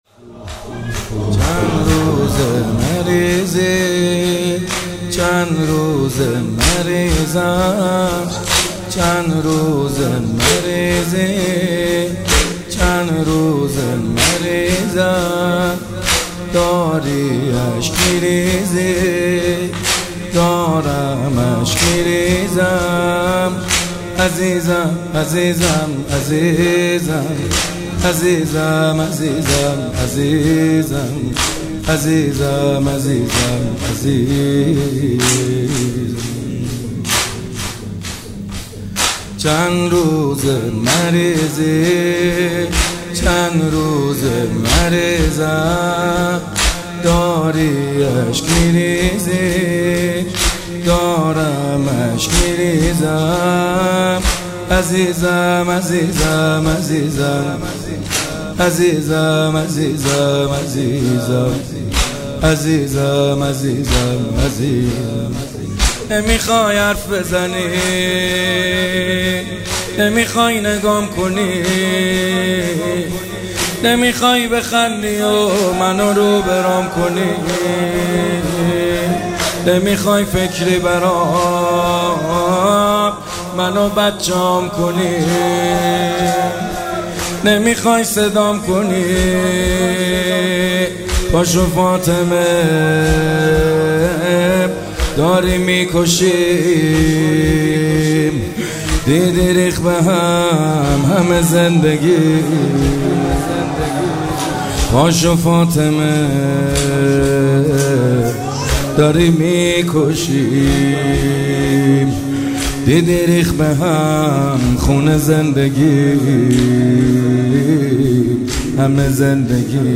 شب اول فاطمیه دوم 1402 - شور - چند روضه مریضی چند روزه مریضم - محمد حسین حدادیان
شب اول فاطمیه دوم 1402